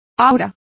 Also find out how auras is pronounced correctly.